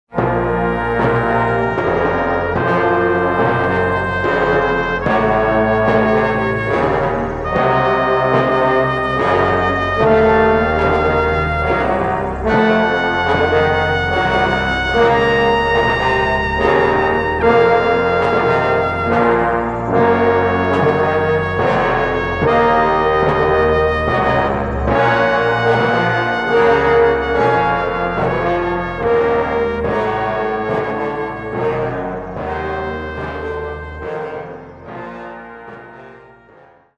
wordless female soprano voice
strong South American influence with pan pipes
ominous sounding tolling church bell